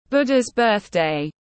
Ngày Lễ Phật Đản tiếng anh gọi là Buddha’s birthday, phiên âm tiếng anh đọc là /ˈbʊdəz ˈbɜːθdeɪ/
Buddhas-birthday-.mp3